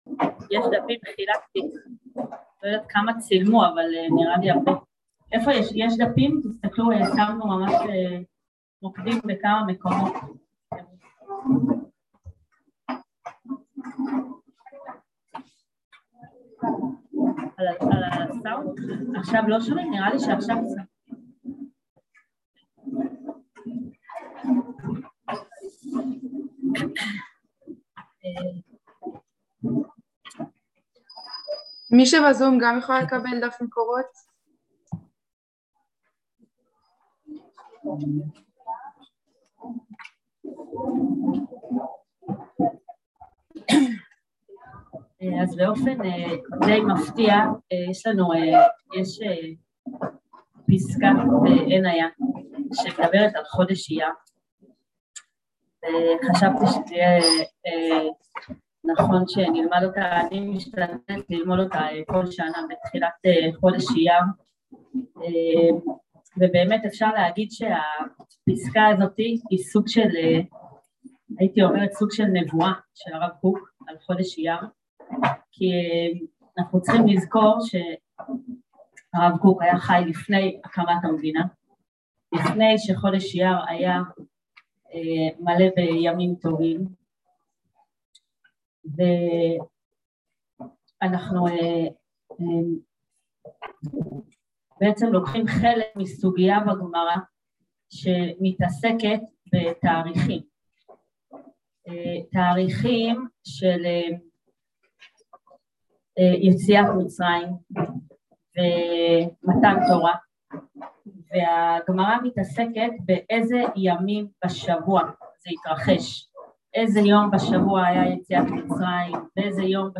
עבודת החיבורים של חודש אייר | יום עיון לקראת יום העצמאות תשפ"ב | מדרשת בינת